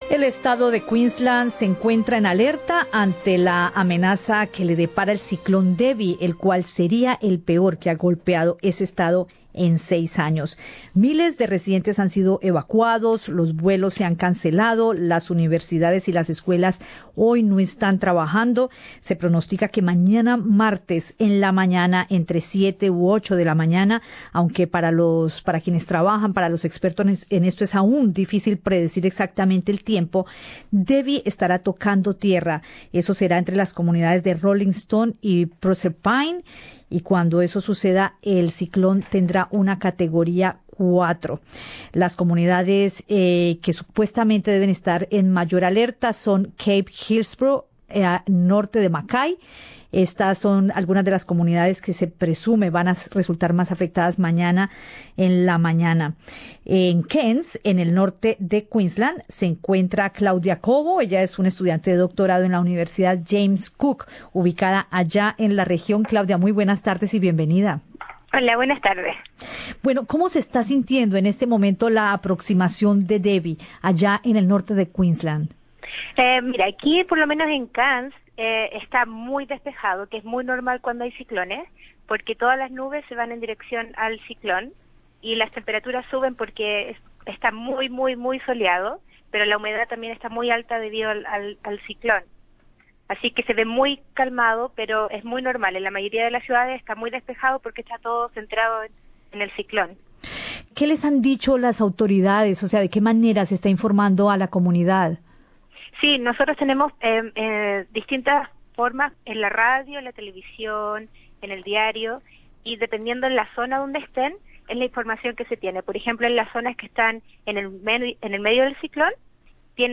En radio SBS conversamos